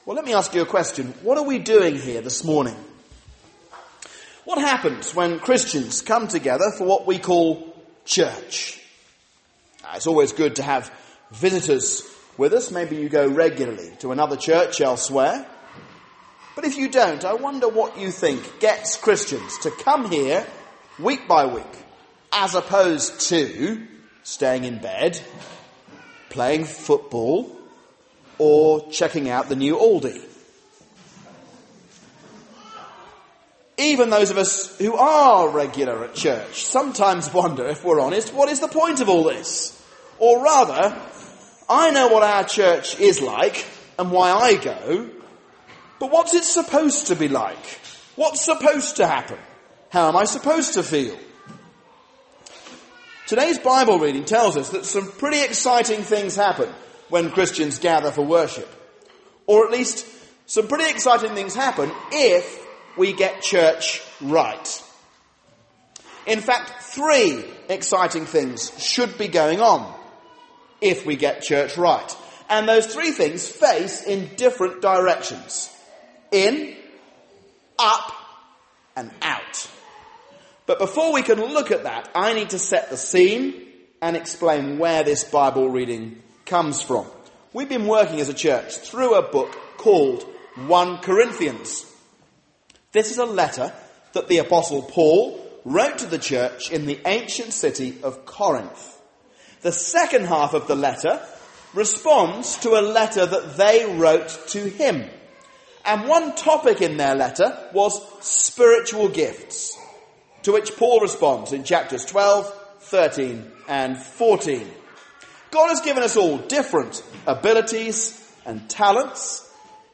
A sermon on 1 Corinthians 14:1-25